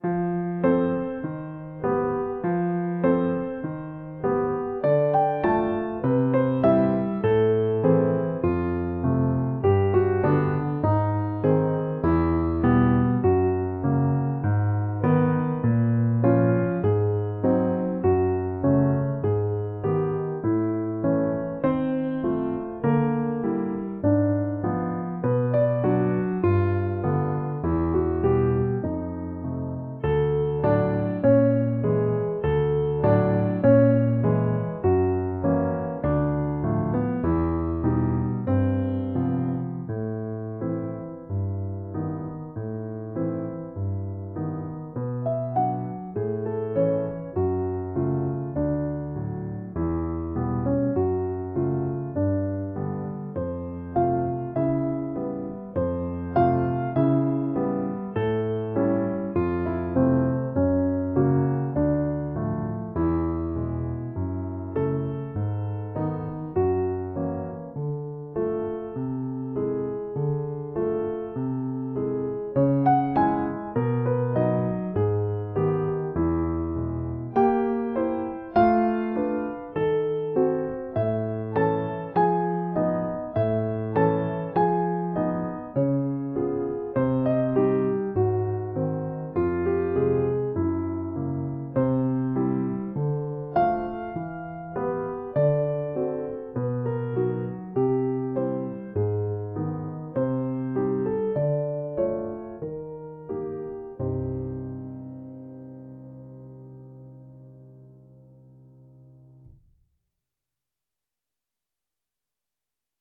- 生ピアノ（生演奏） RPG